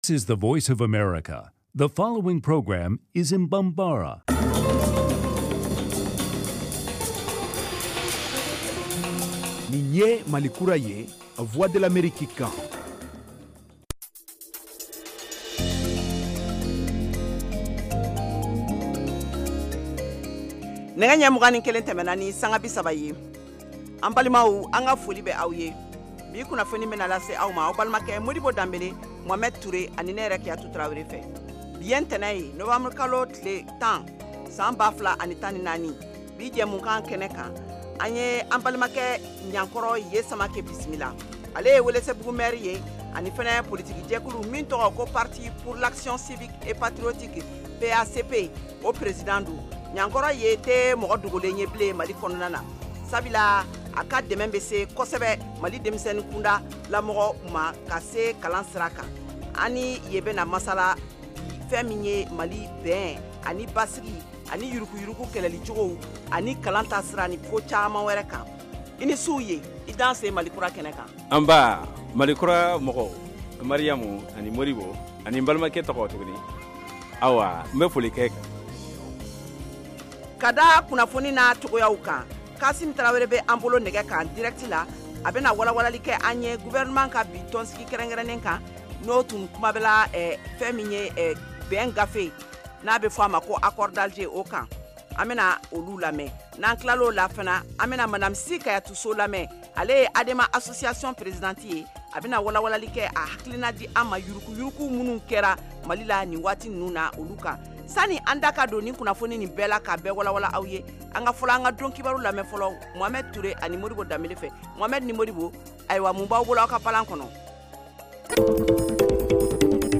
Emission quotidienne en langue bambara
en direct de Washington